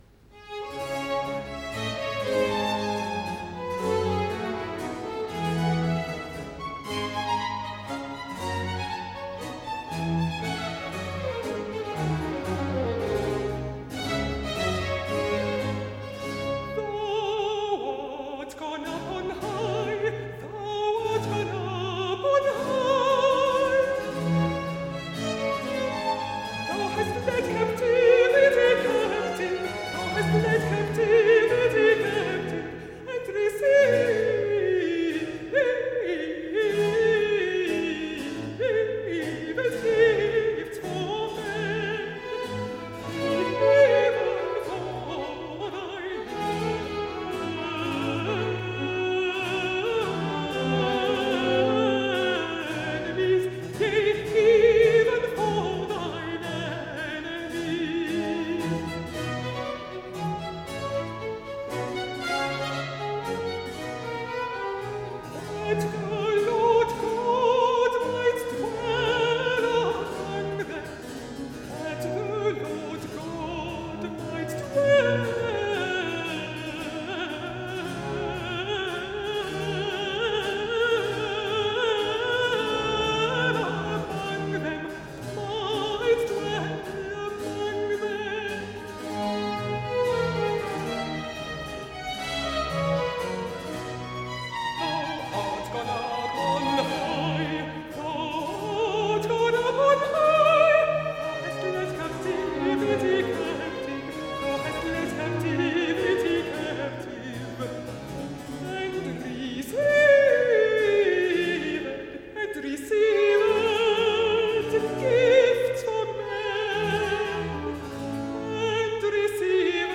Aria-alto